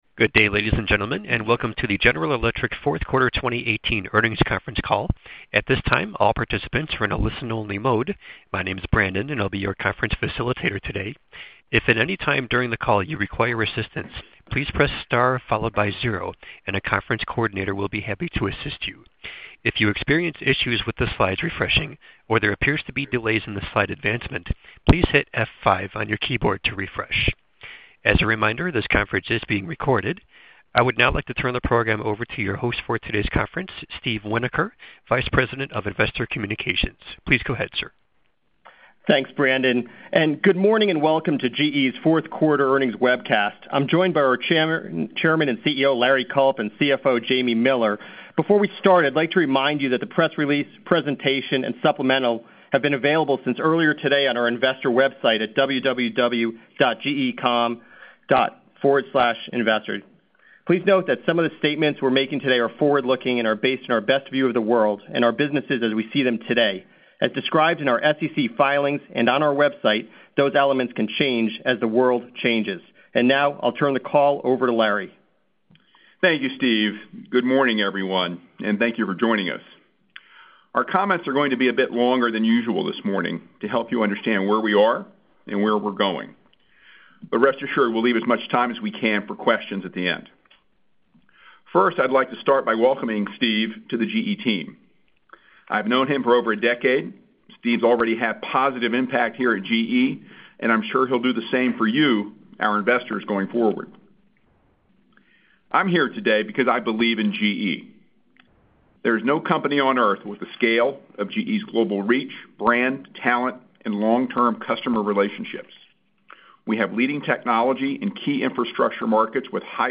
GE Chairman & CEO H. Lawrence Culp, Jr. presented.